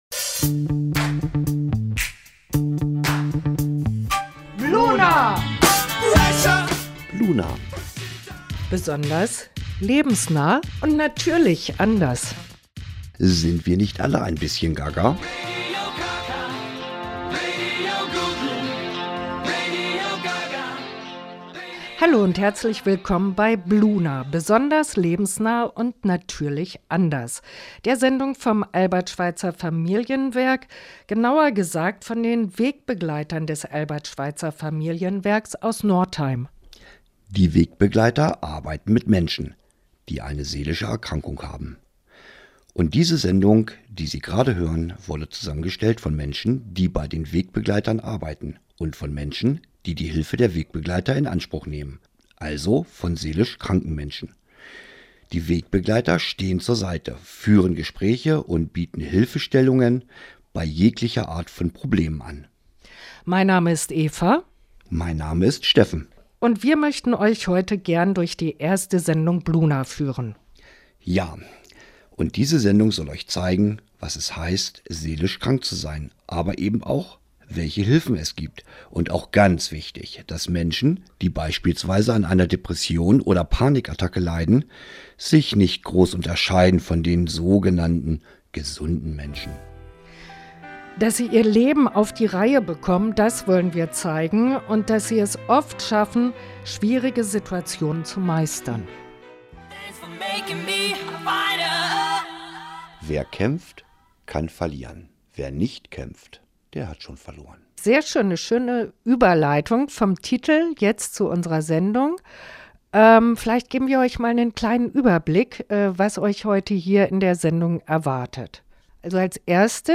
Unten im Artikel finden Sie den Mitschnitt – aus kostengründen allerdings ohne Musikbeiträge.